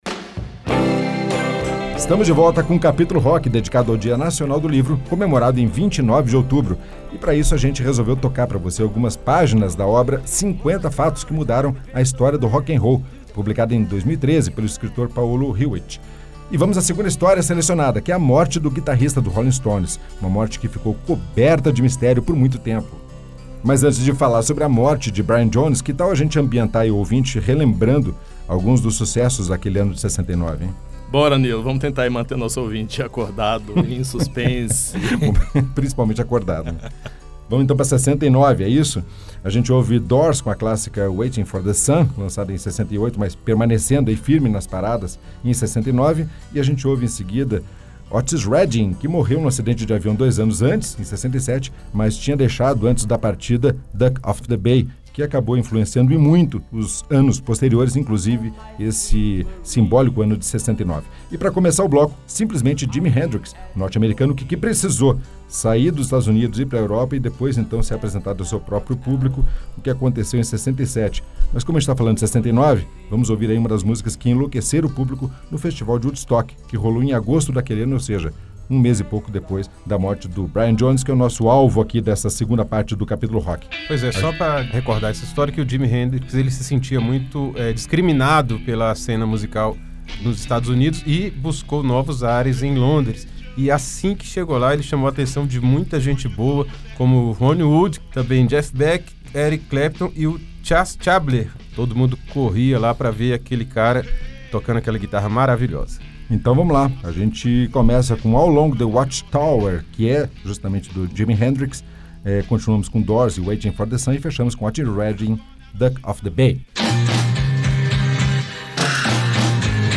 Conheça um pouco dessa obra ao som de muito rock n’ roll no Capítulo Rock desta semana.